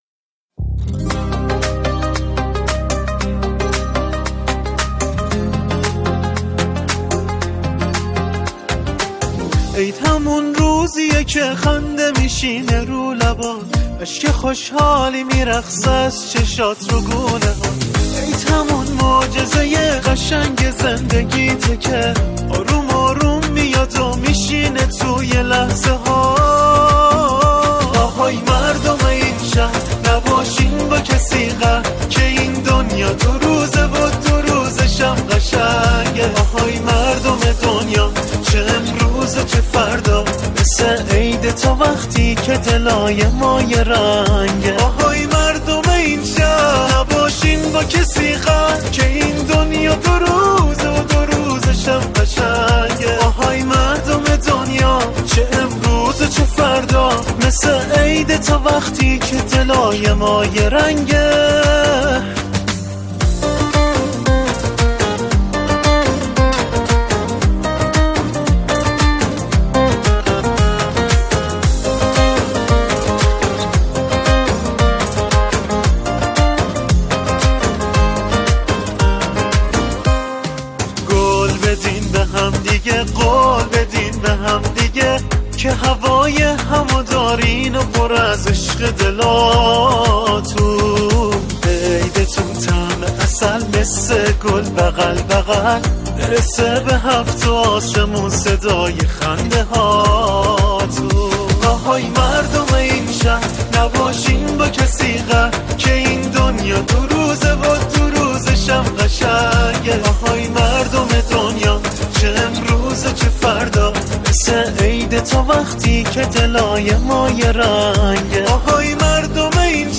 عید نوروز
این قطعه نمونه‌ای از سرود با اجرای یک خواننده اصلی است.